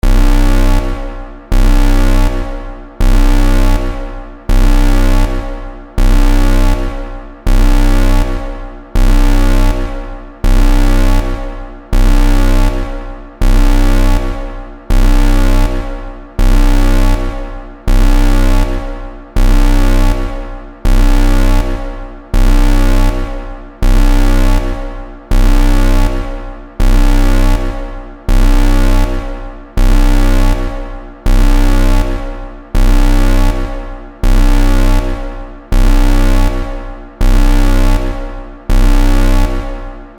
SFアラームループ01